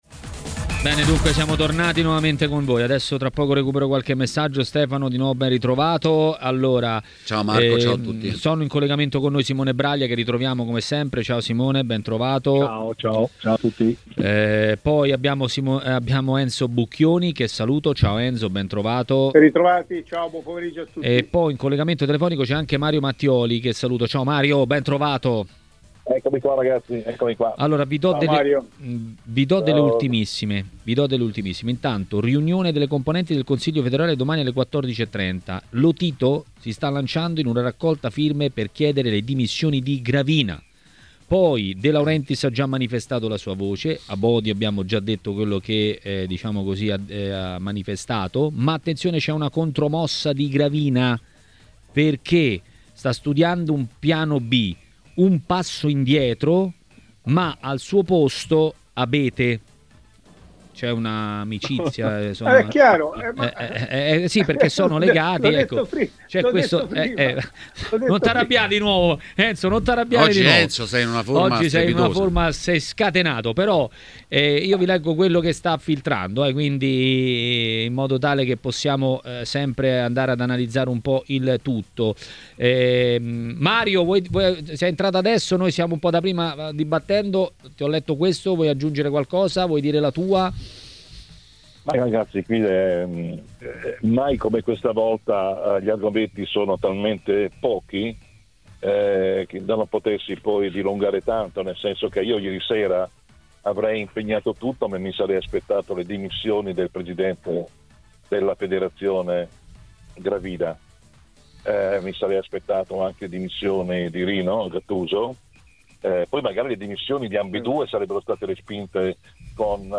A parlare dei temi del giorno a TMW Radio, durante Maracanà